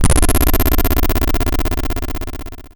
swarm.wav